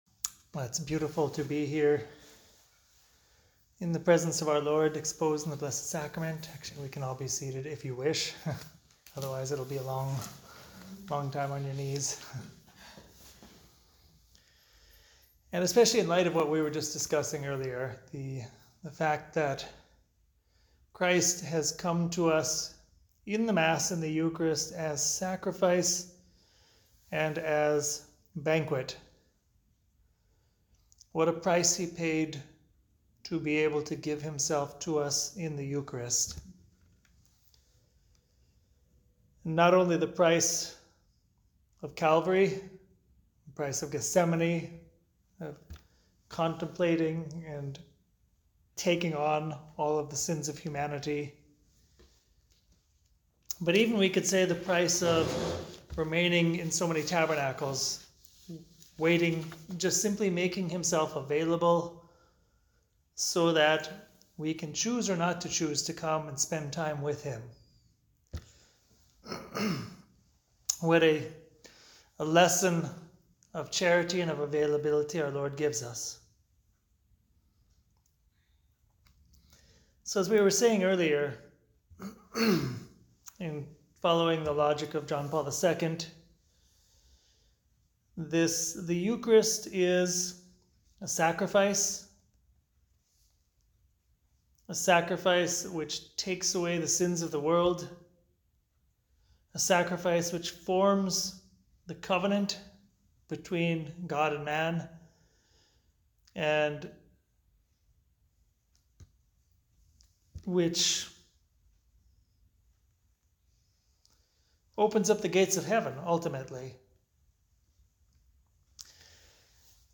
NJ Evening Retreat Talk and Meditation - RC NY Tri-State